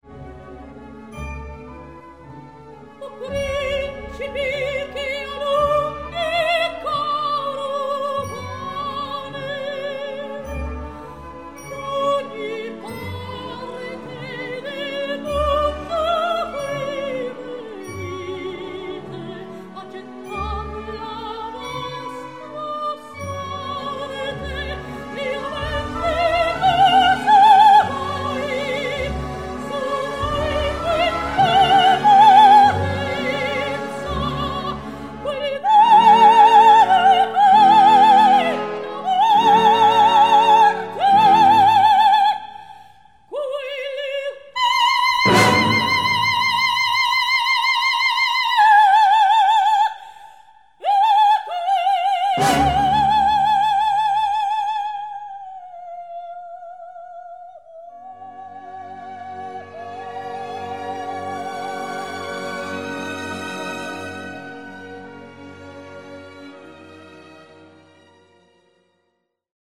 DALL’ATTO SECONDO, ARIA: